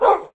mob / wolf / bark1.ogg
bark1.ogg